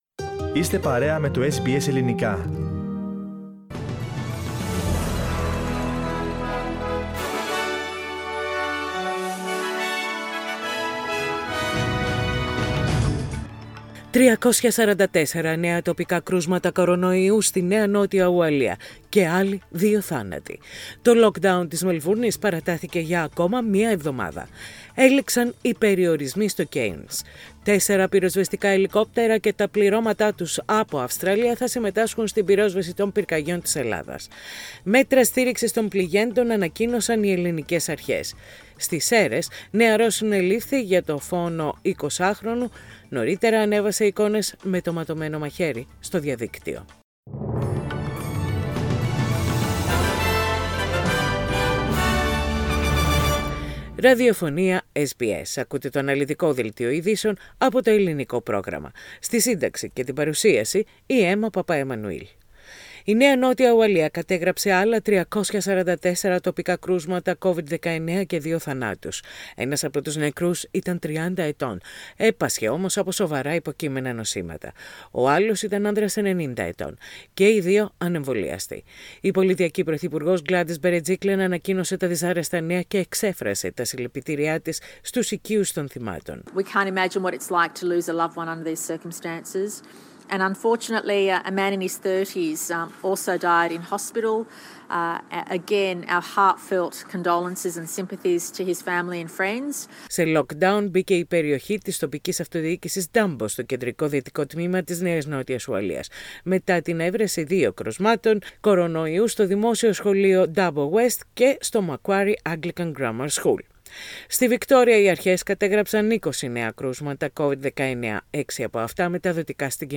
The main news of the day from Australia, Greece, Cyprus and the rest of the world, as presented by the Greek program of SBS radio.